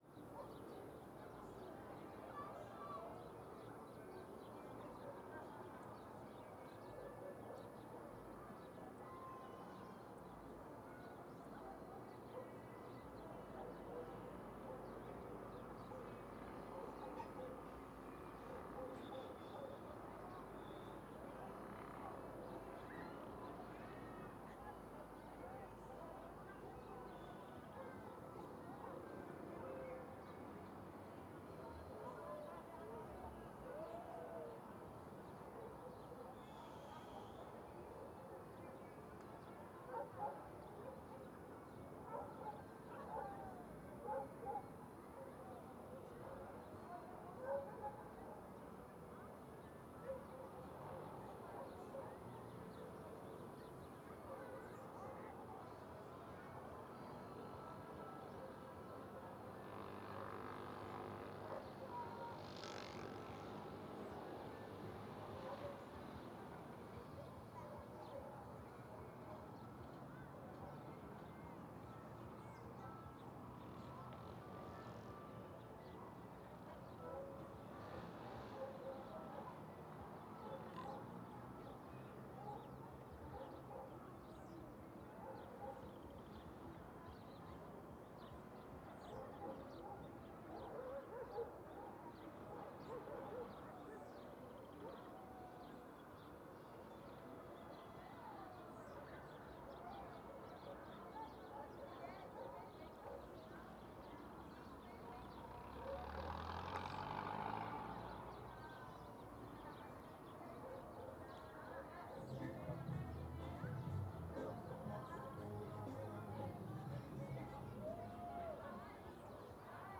CSC-03-018-GV - Ambiencia no Mirante da Cidade de Alto Paraiso com sons da Cidade longe.wav